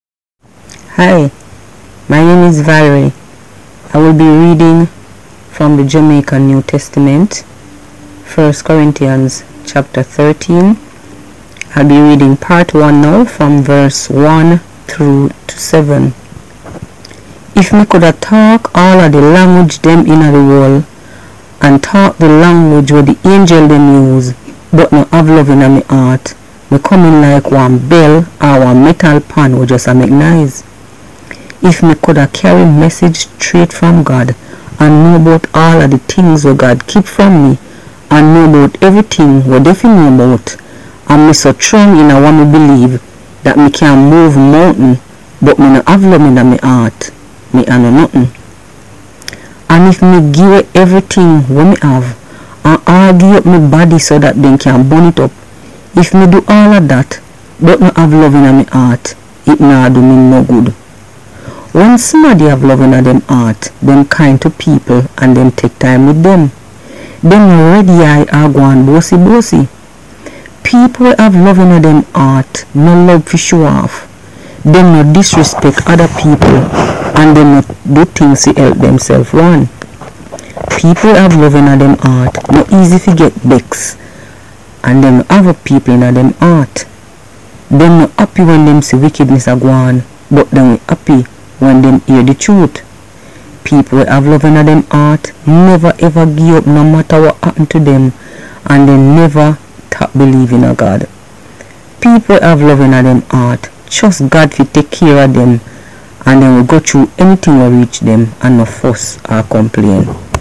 In an effort to improve learning and develop better understanding of the Christian Holy Book - The Bible – each week we’ll present scripture reading in Patois (pronunciation patwa) or Jamaican Creole.
Today’s scripture reading is Part I of 1st Corinthians Chapter 13, versus 1-7.